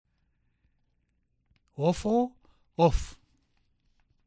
I.  Listen to the difference between o and ö.  This difference only shows up in the short forms.